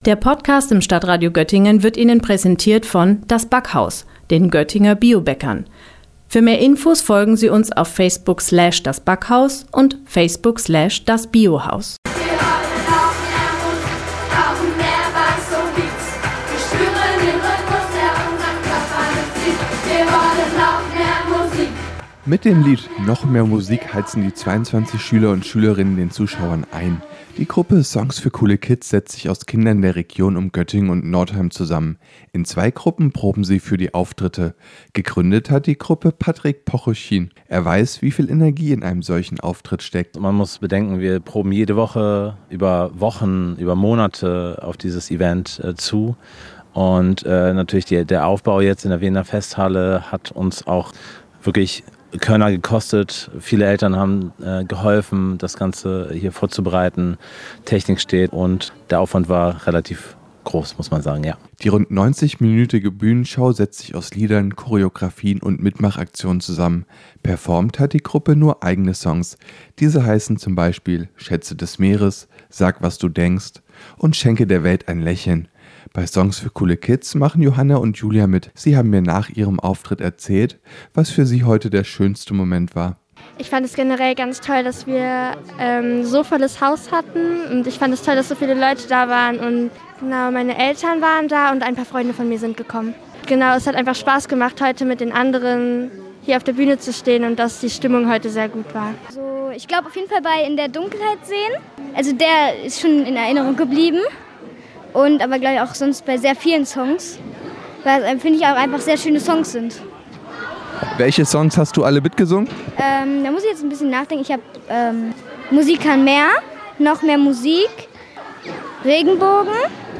Beiträge > Es schallte aus der Weender Festhalle: Schülerband „Songs für coole Kids“ performt vor vollem Saal - StadtRadio Göttingen